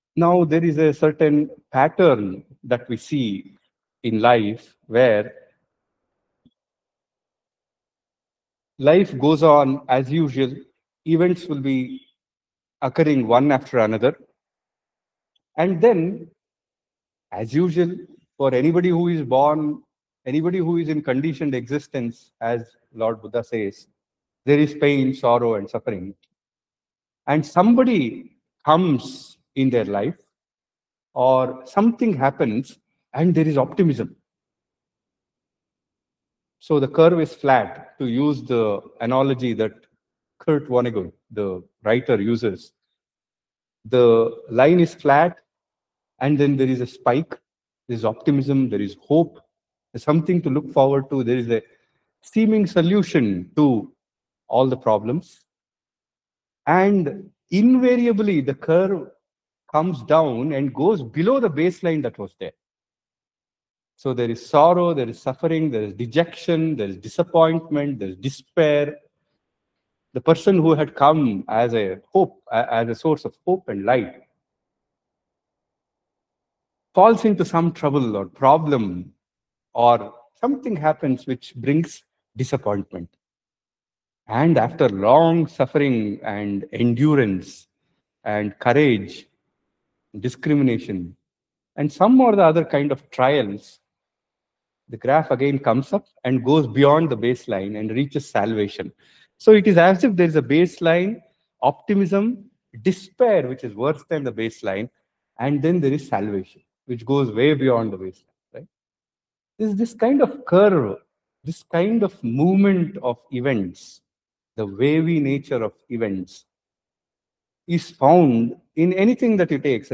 Dear Subscriber, An Audio Lecture entitled Resurrection of Christ has been recorded and uploaded to our web site.